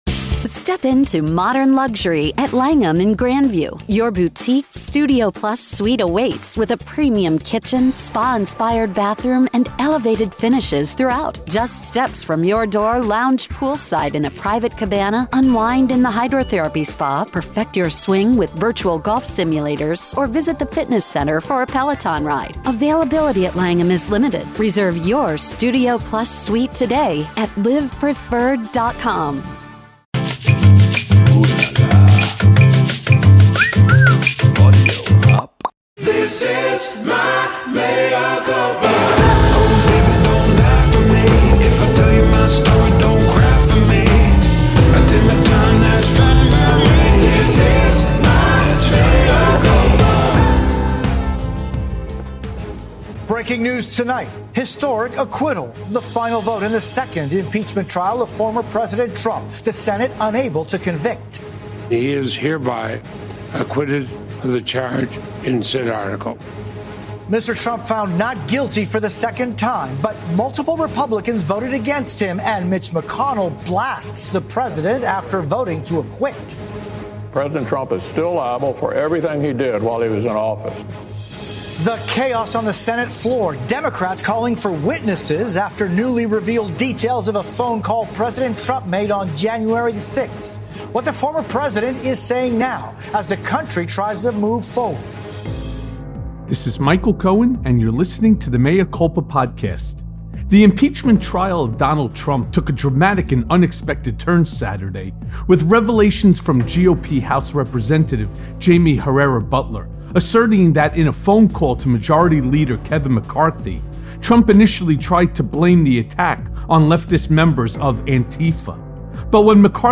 Later Michael speaks with “All Gas No Brakes” creator Andrew Callaghan about conspiracy, the Proud Boys and how half this country brainwashed itself into becoming lunatic MAGA supporters.